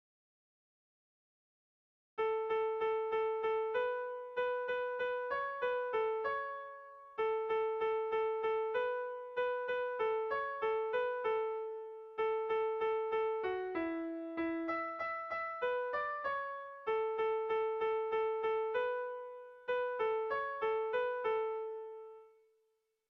Kontakizunezkoa
Zortziko txikia (hg) / Lau puntuko txikia (ip)
A1A2BA2